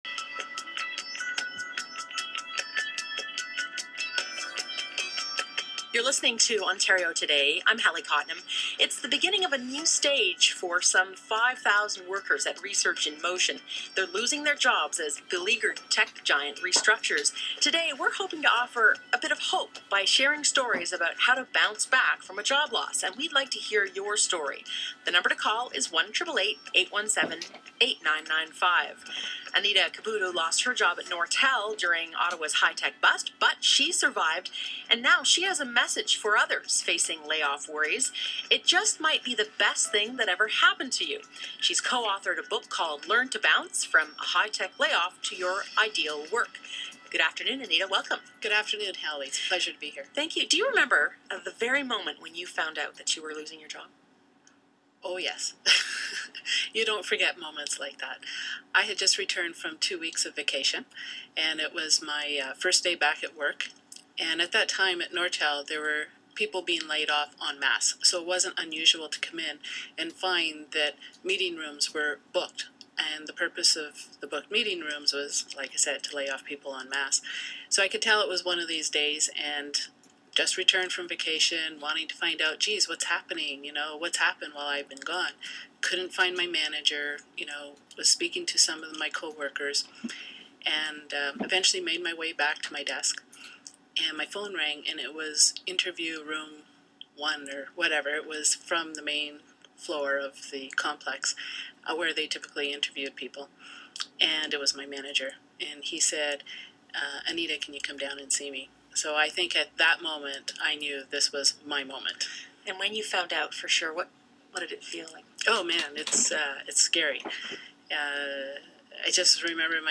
Podcasts and Interviews